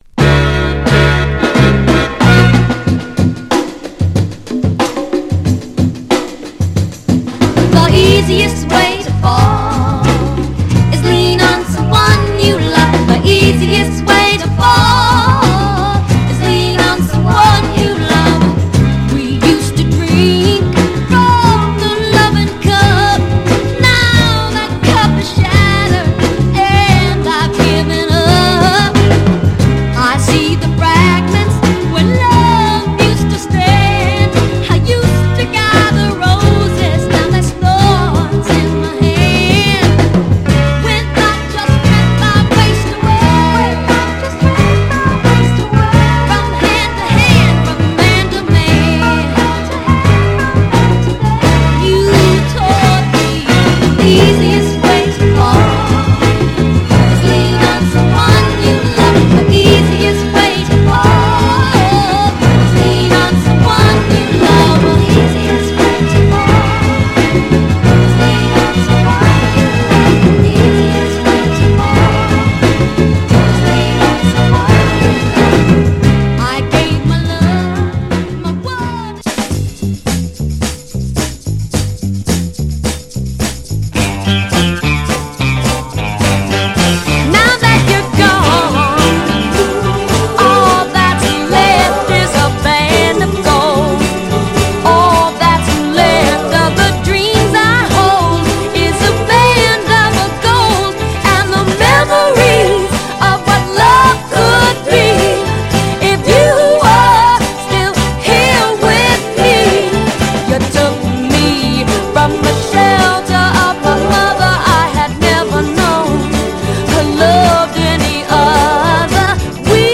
盤はエッジ中心に細かいスレ、細かいヘアーラインキズ箇所ありますが、グロスが残っておりプレイ良好です。
※試聴音源は実際にお送りする商品から録音したものです※